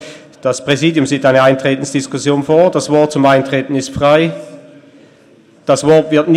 Session des Kantonsrates vom 23. und 24. April 2018